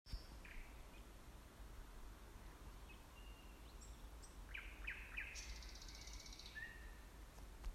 Птицы -> Дроздовые ->
соловей, Luscinia luscinia
СтатусСлышен голос, крики